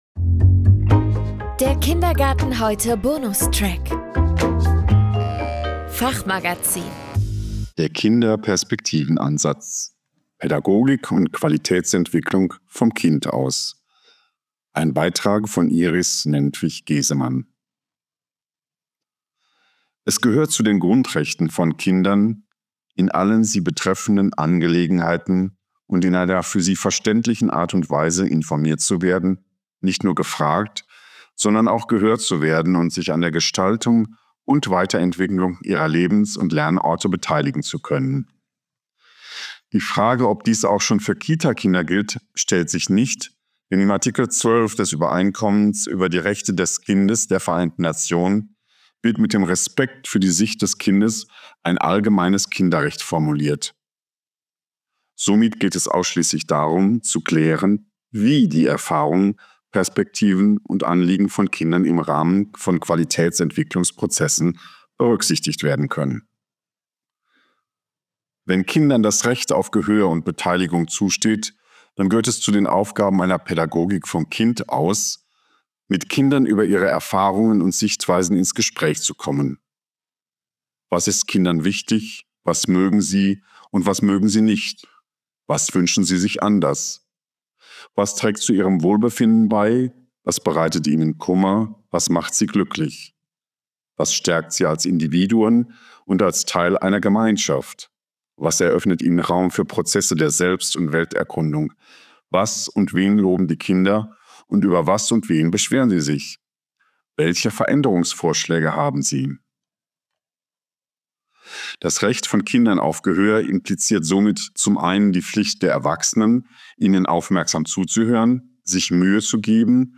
Vorgelesen: Mitbestimmung macht die Kita besser - und den Kita-Alltag einfacher Was wäre, wenn Kinder entscheiden, ob ihre Kita gut oder schlecht ist?